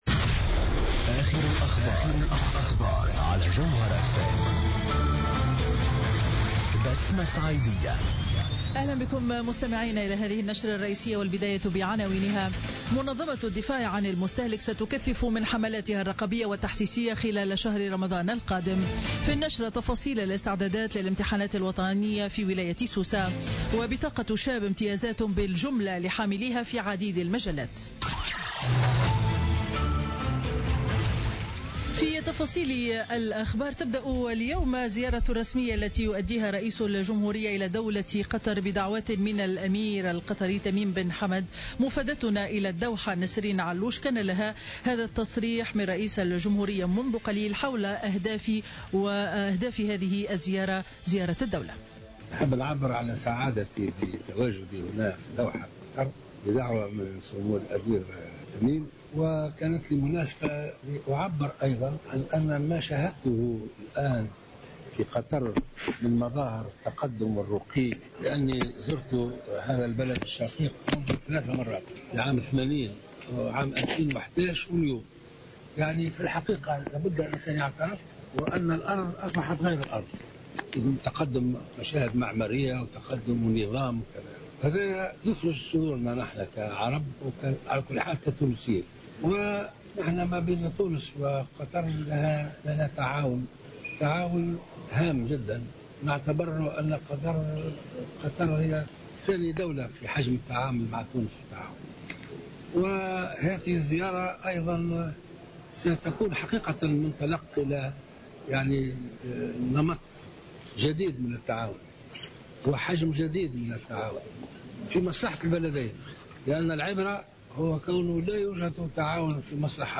Journal Info 12h00 du mercredi 18 mai 2016